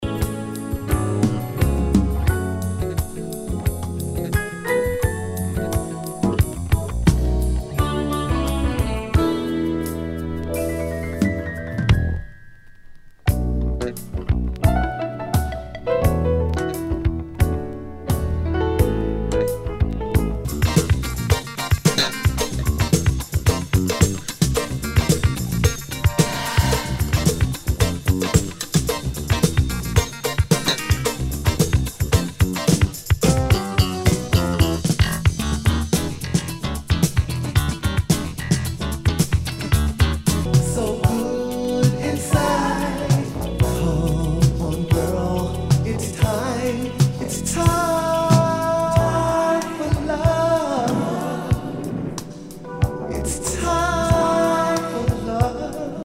SOUL/FUNK/DISCO
ナイス！ジャズ・ファンク！